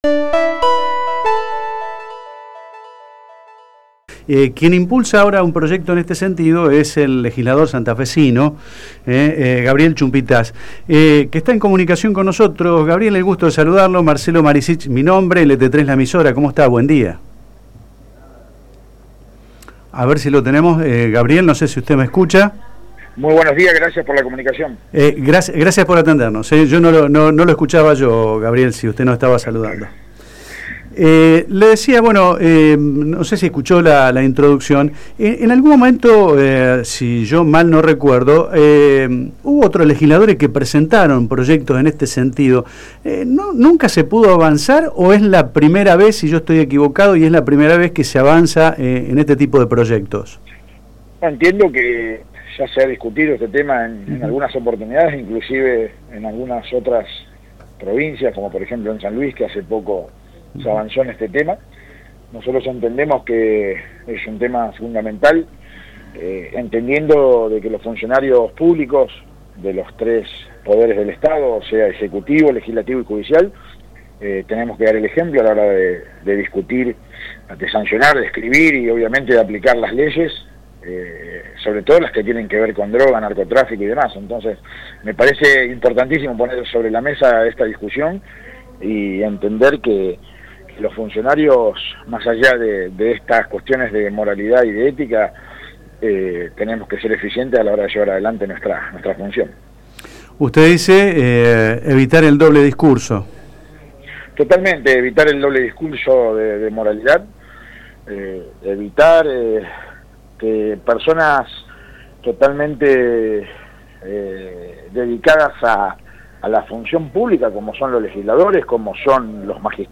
En comunicación con “La Barra de Casal” el diputado nacional por la provincia de Santa Fe, Gabriel Chumpitaz se refirió al proyecto de ley que plantea la realización de exámenes toxicológicos obligatorios para funcionarios de los 3 poderes del estado.